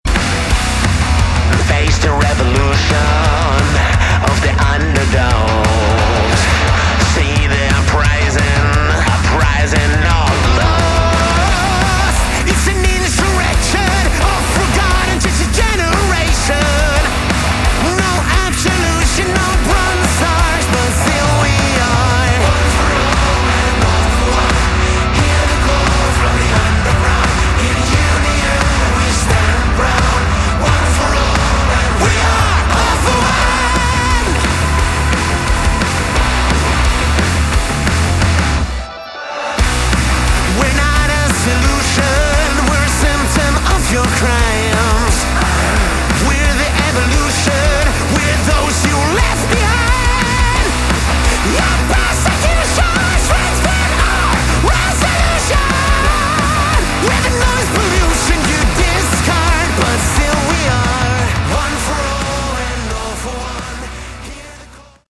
Category: Melodic Rock
vocals
guitars
drums
bass